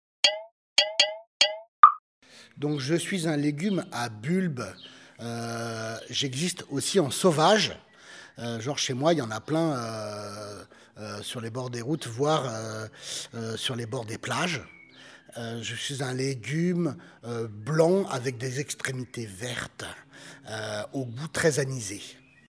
…des devinettes sonores